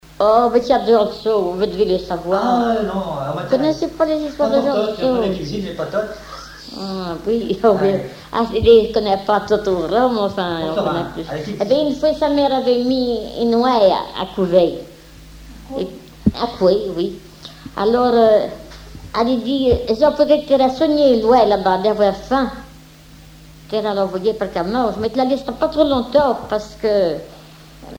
Genre conte
Enquête La Soulère, La Roche-sur-Yon
Catégorie Récit